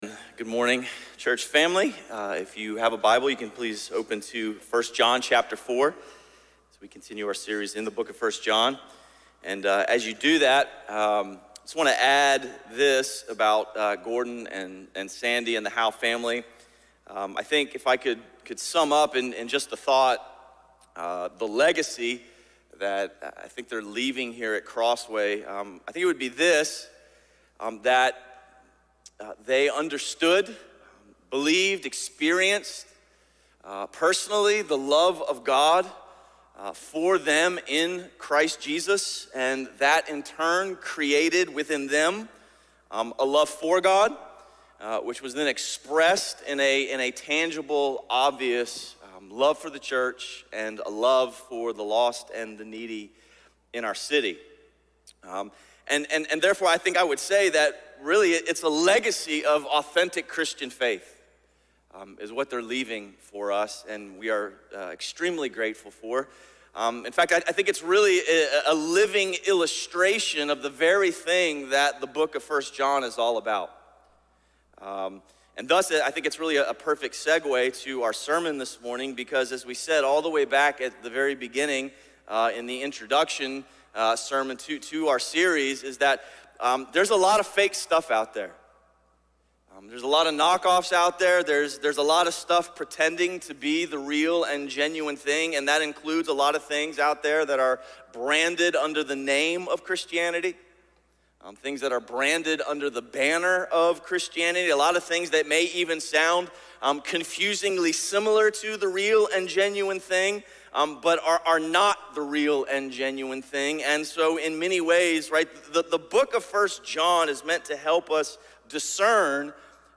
A message from the series "Life Under the Sun."